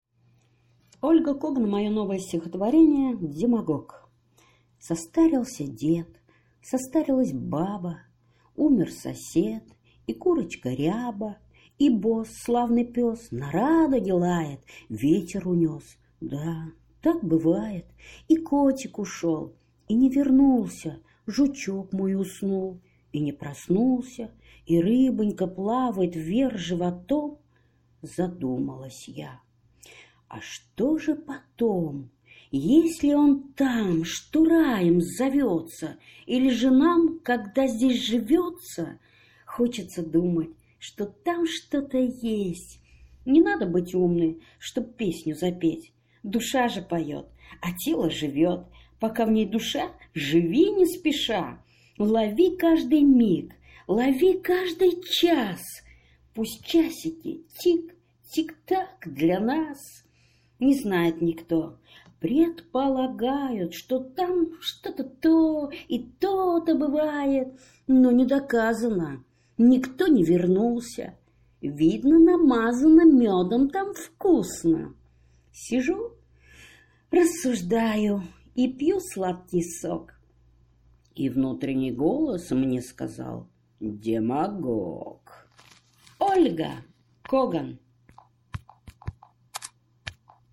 Читаю авторское стихотворение.